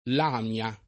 vai all'elenco alfabetico delle voci ingrandisci il carattere 100% rimpicciolisci il carattere stampa invia tramite posta elettronica codividi su Facebook Lamia [ l # m L a ] top. (Gr.) — gr. mod. Λαμία / Lamía [ lam & a ]